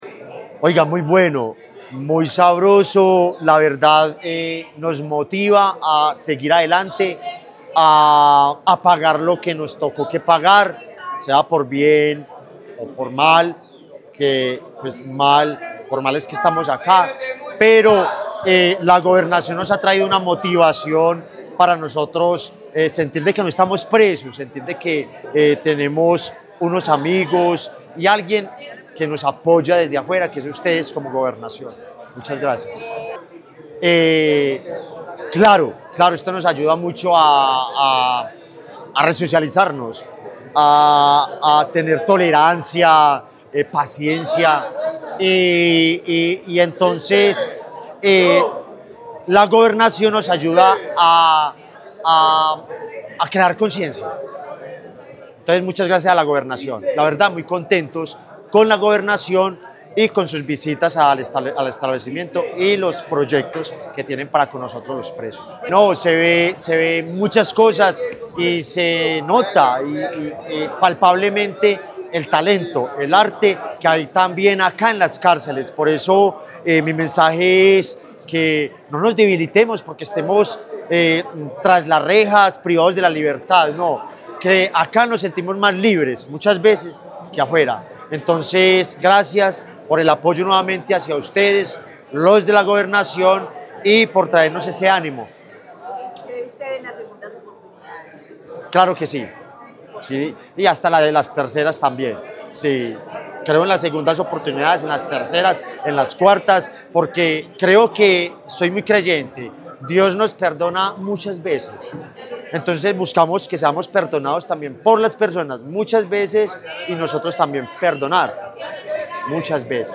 Persona privada de la libertad participante del proyecto.